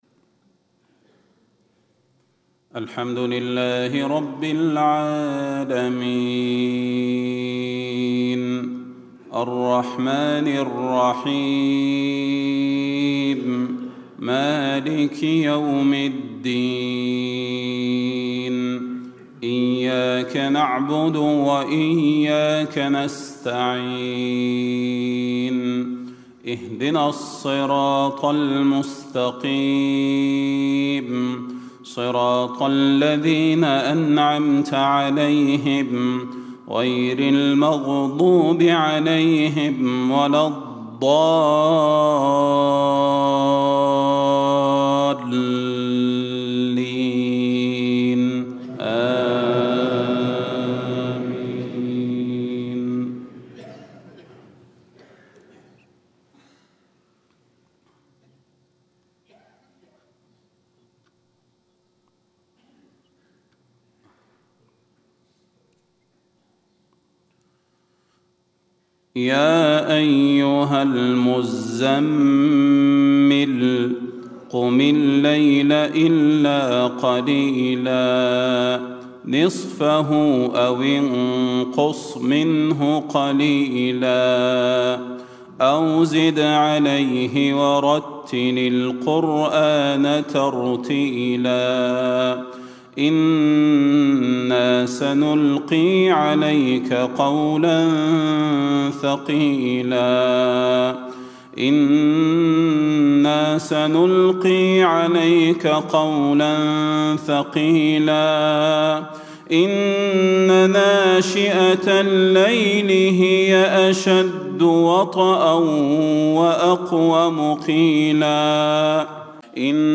صلاة الفجر 25 ربيع الاخر 1437هـ سورة المزمل > 1437 🕌 > الفروض - تلاوات الحرمين